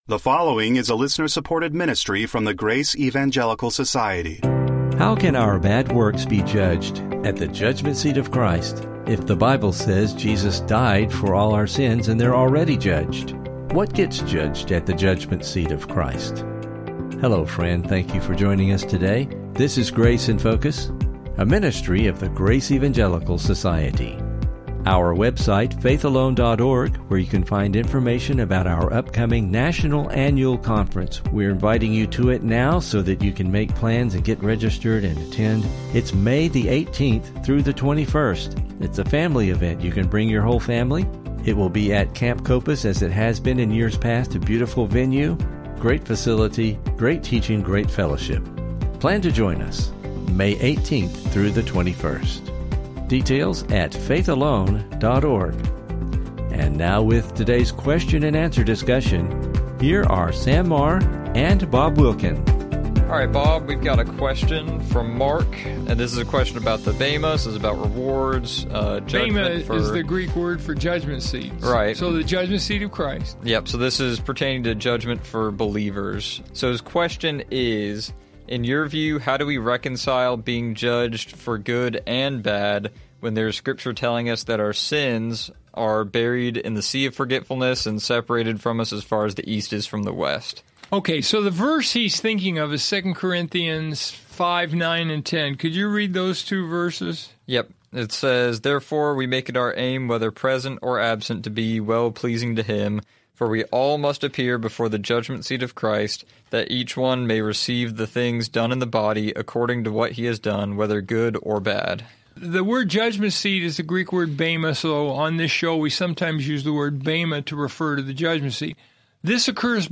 If Jesus has already taken the judgment for believers’ sins, how does this work at the Judgment Seat of Christ? Please listen for a challenging discussion and never miss an episode of the Grace in Focus Podcast!